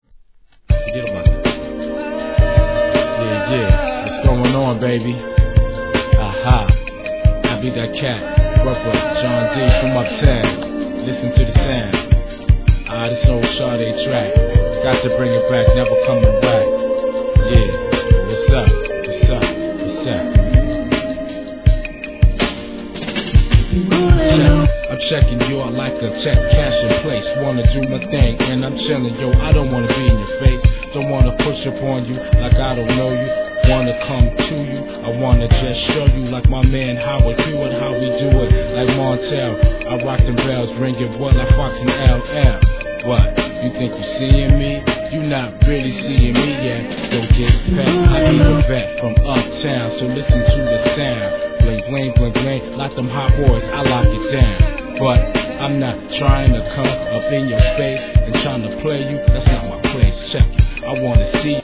HIP HOP/R&B
UP TOWN CLUB VERSION 02 FLOW